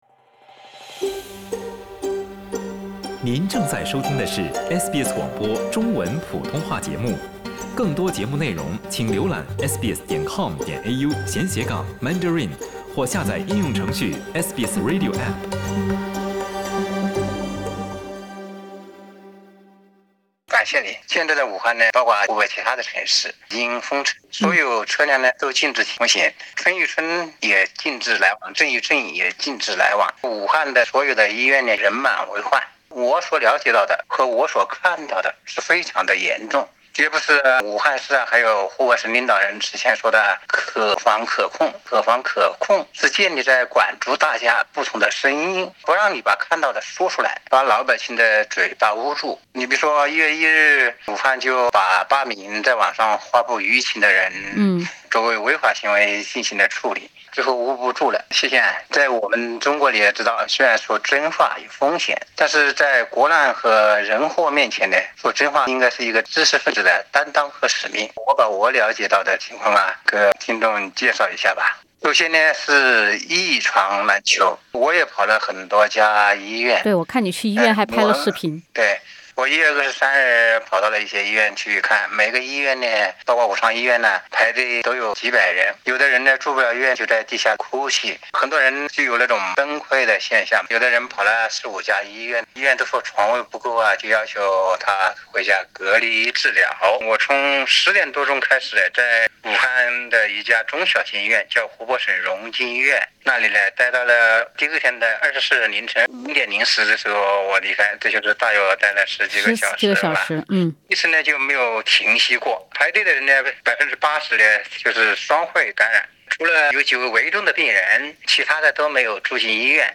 下面我就通过这段对话，来感受一下武汉所经历的痛苦。